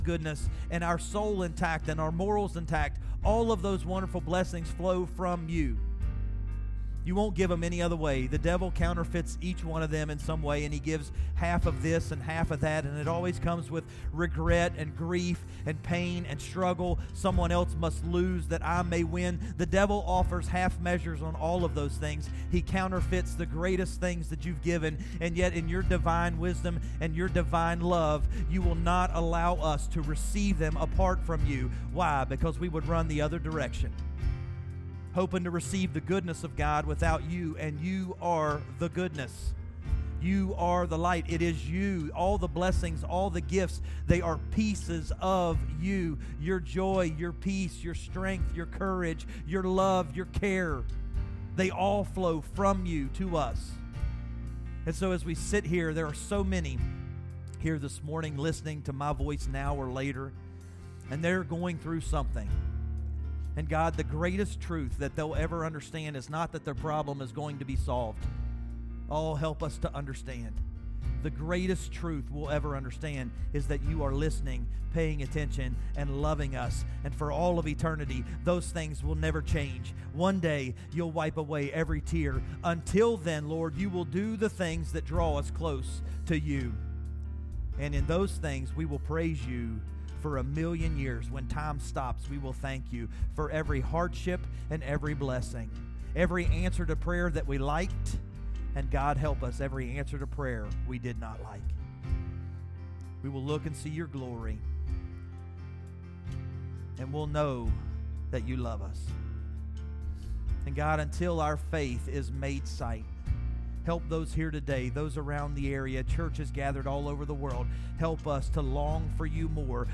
Sermons by HBCWV